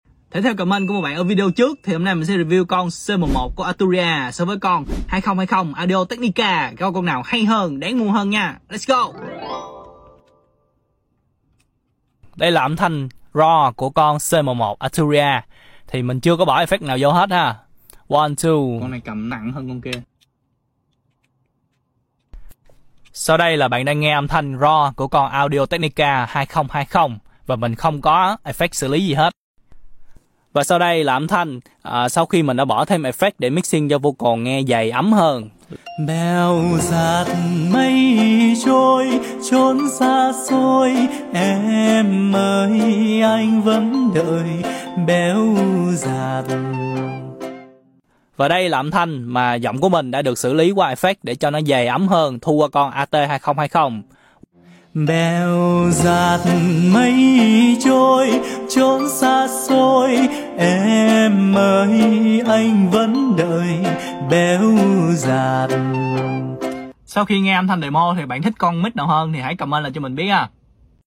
[Review Microphone] Audio Technica AT2020 Sound Effects Free Download
[Review Microphone] Audio Technica AT2020 vs Arturia CM1